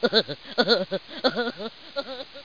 00586_Sound_giggle.mp3